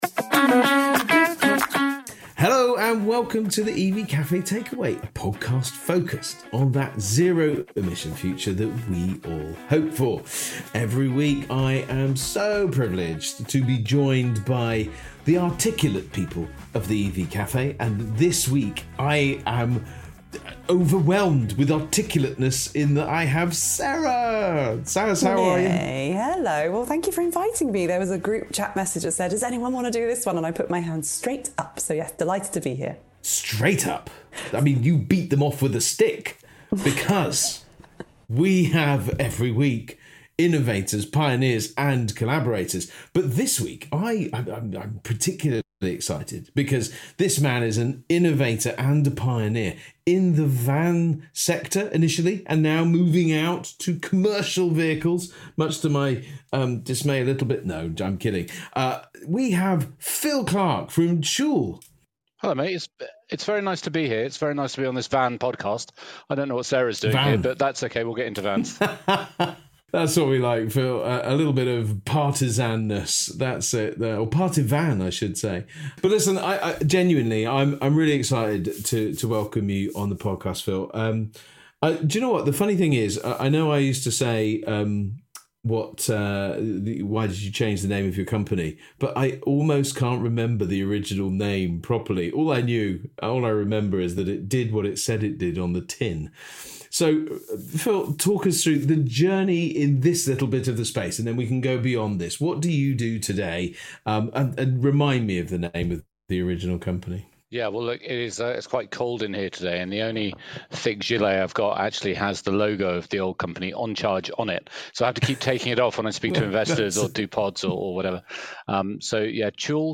Discover how “perpetual” thinking and portable energy storage can help fleets sidestep lengthy DNO upgrades, reduce downtime, and drive the energy transition forward. From rural depots to bustling city hubs, this lively conversation offers an inspiring blueprint for the zero-emission journeys that lie ahead.